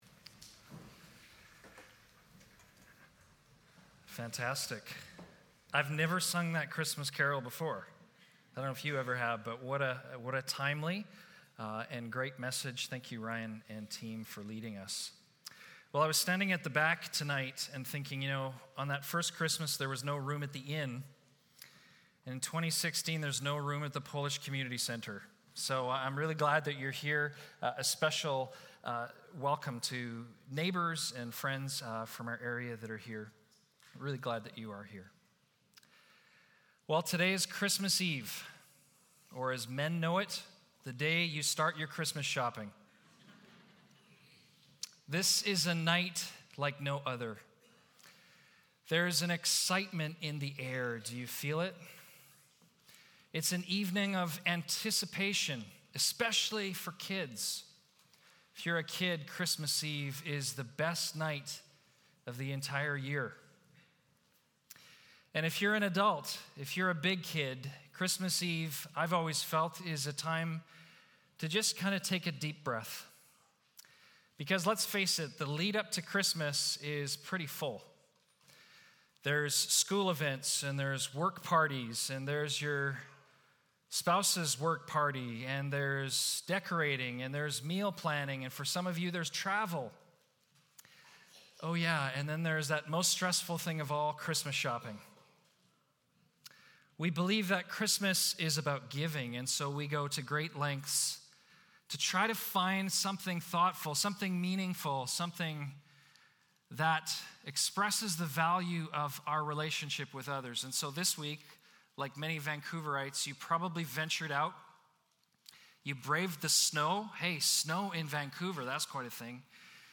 Christmas Eve service
Episode from Tenth Church Sermons